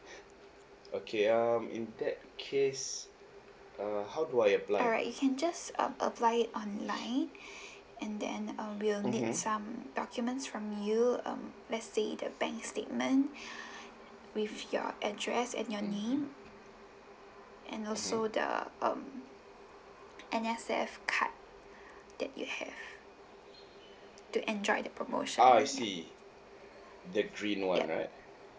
IMDA_conversation.wav